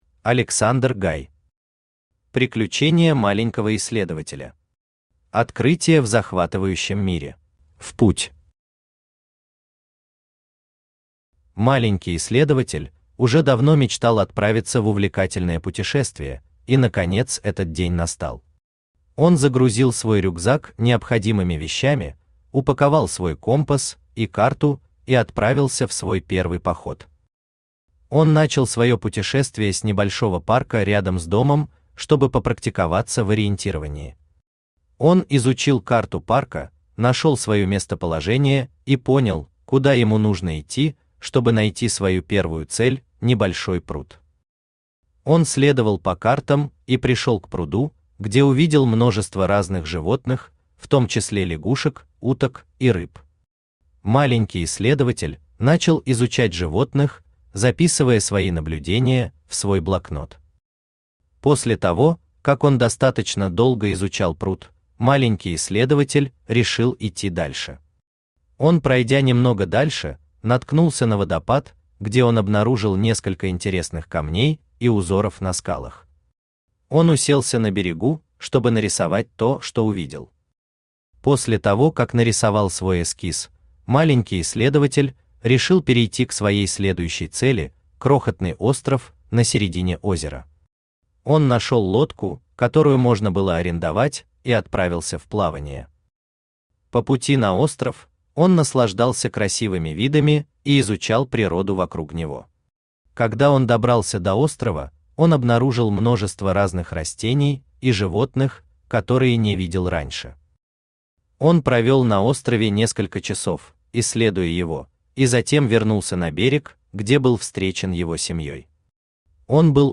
Аудиокнига Приключения маленького исследователя. Открытия в захватывающем мире | Библиотека аудиокниг
Открытия в захватывающем мире Автор Александр Гай Читает аудиокнигу Авточтец ЛитРес.